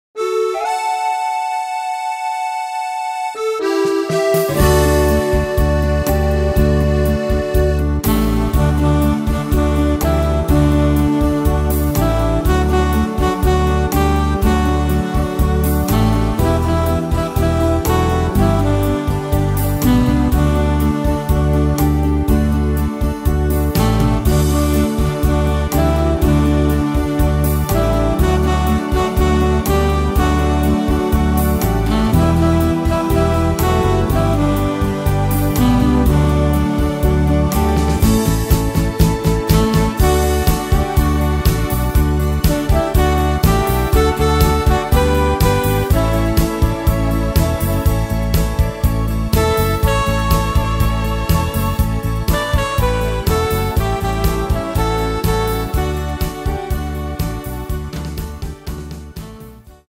Tempo: 122 / Tonart: D / Eb -Dur